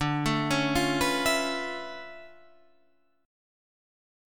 D Minor Major 13th